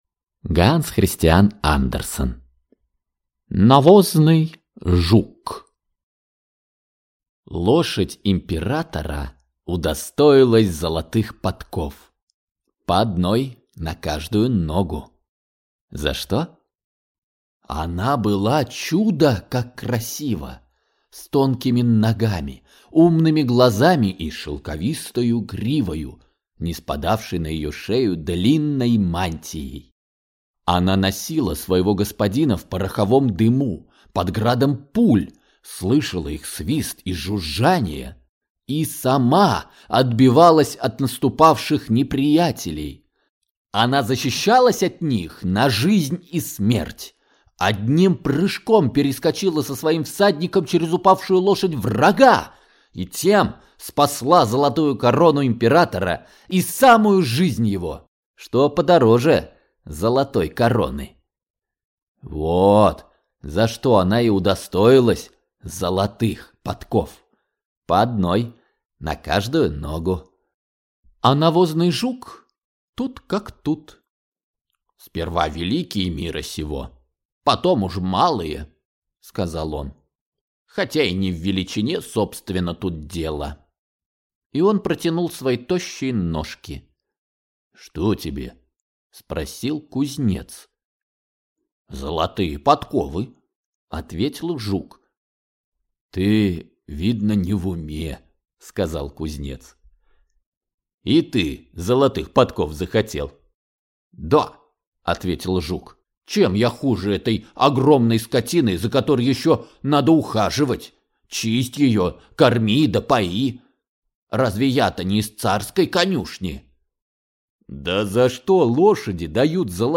Аудиокнига Навозный жук | Библиотека аудиокниг